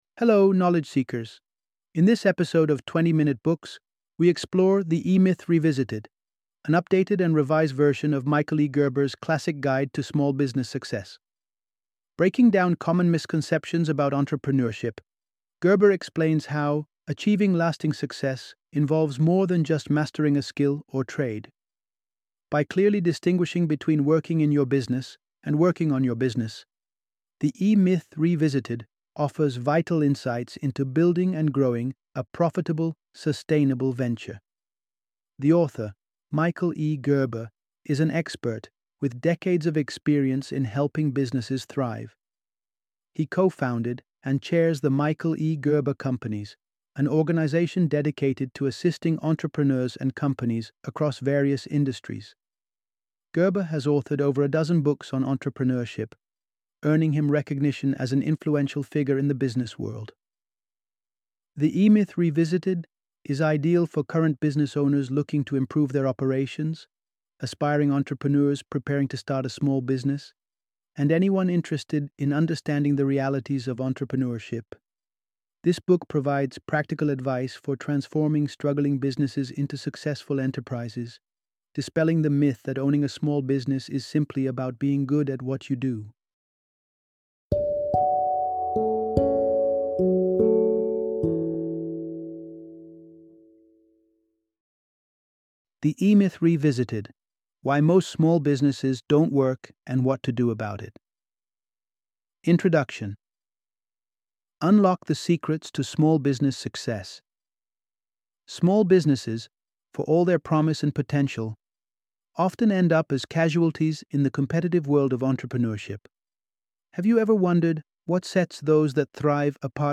The E-Myth Revisited - Audiobook Summary